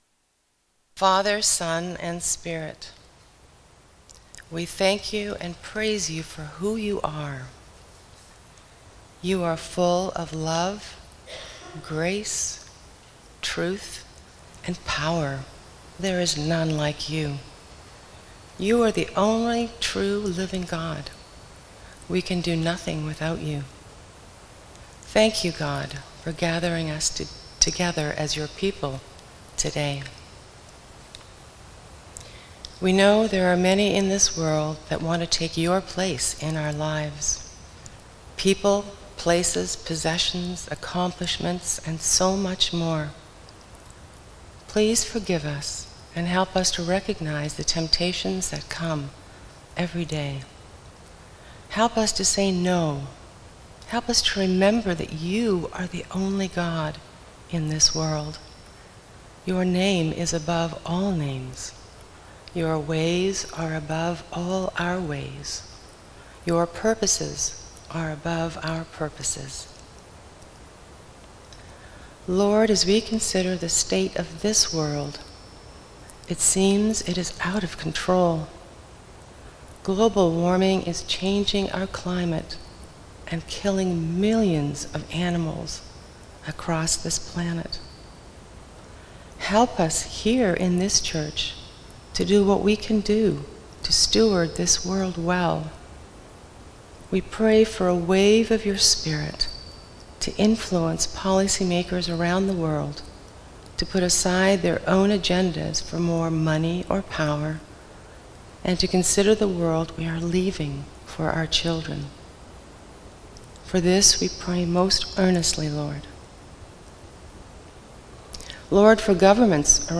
Sermons | Fellowship Christian Reformed Church of Toronto